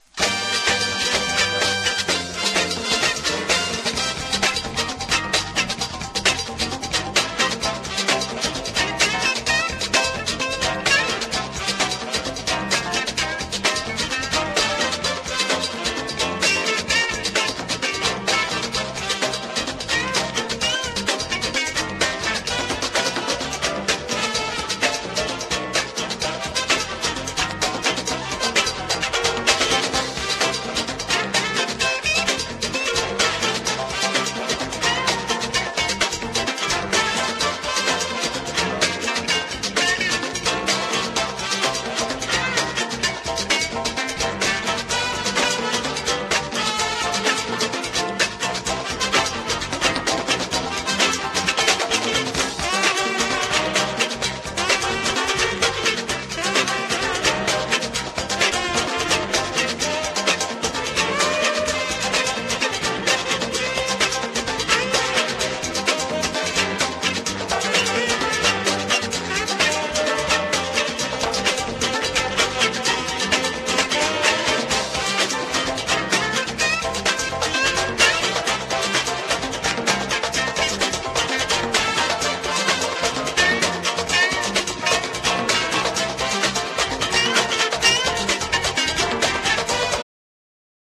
商品情報 男女ヴォーカルをフィーチャーしたパーティー・ラテン人気盤！！